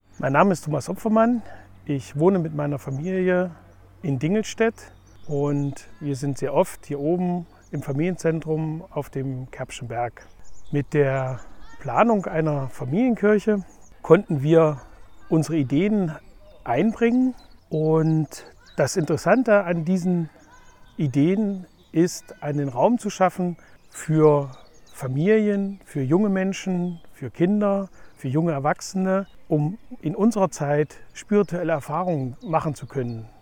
O-Töne und Moderationen zu Ihrer redaktionellen Verwendung
O-TÖNE AUS EINEM DER BEISPIELPROJEKTE: FAMILIENZENTRUM „KERBSCHER BERG“ IN DINGELSTÄDT